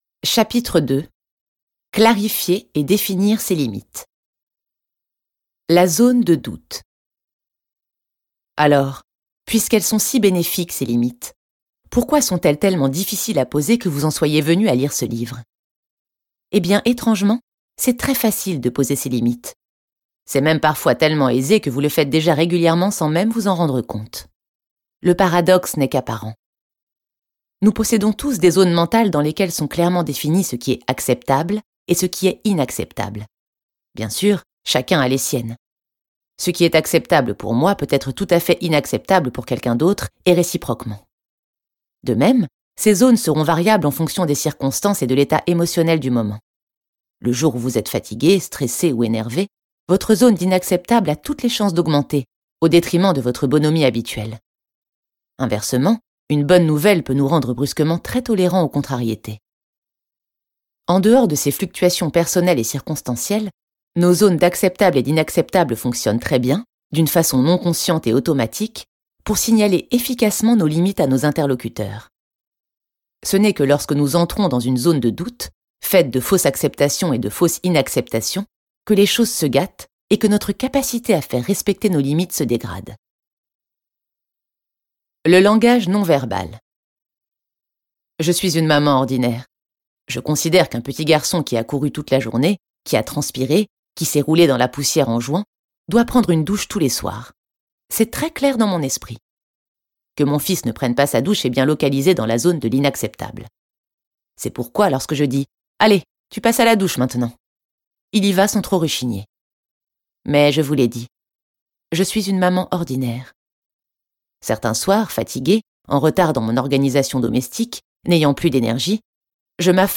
Mais réalisez surtout, vous qui croyez ne pas savoir dire "non", que vous savez en fait très bien le dire mais que vous ne l'avez dit jusqu'à présent qu'à une seule personne : vous-même !Ce livre audio est interprété par une voix humaine, dans le respect des engagements d'Hardigan.